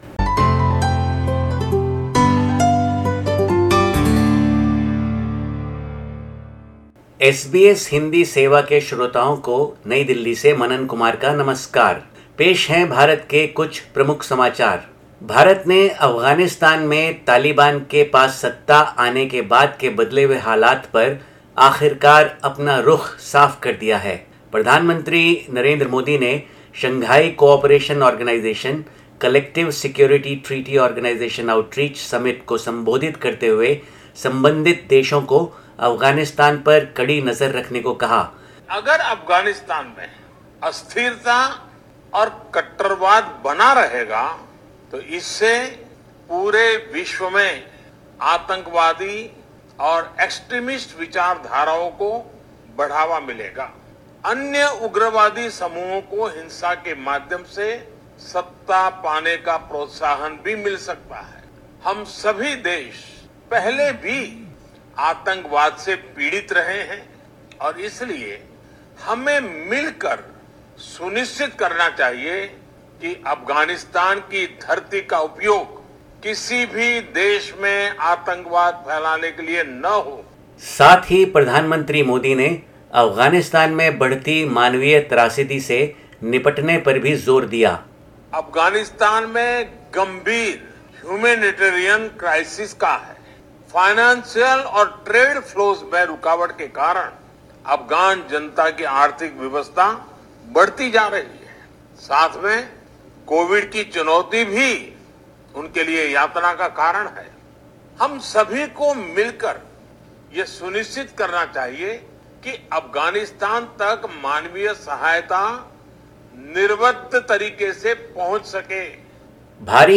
sept_21_sbs_hindi_news_bulletin.mp3